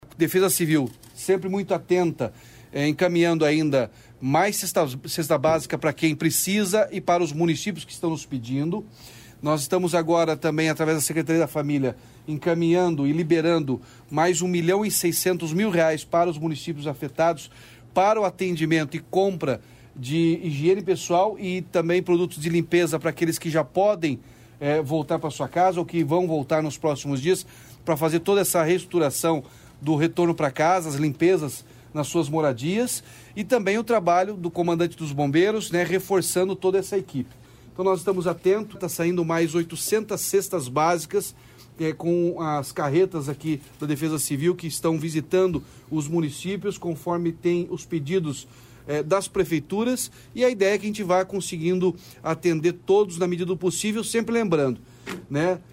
Sonora do governador Ratinho Junior sobre o apoio imediato aos desabrigados e desalojados pelas chuvas